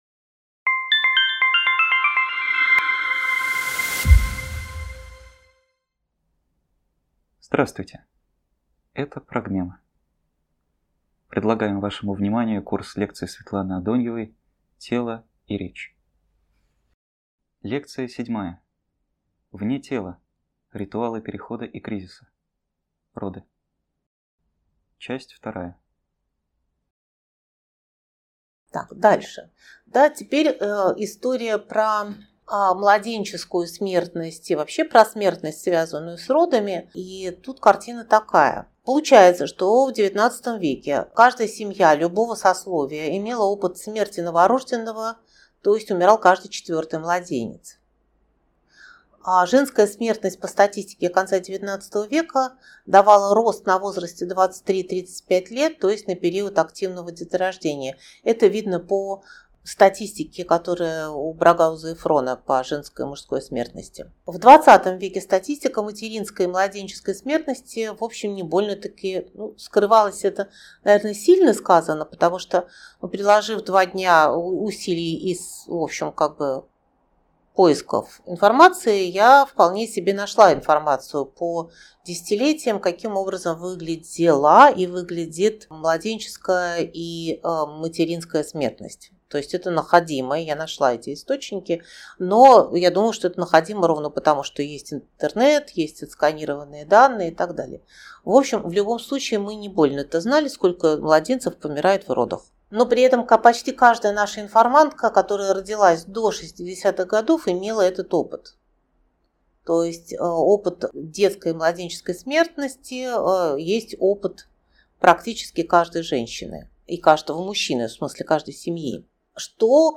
#3.2 Тело и речь. Лекция 3.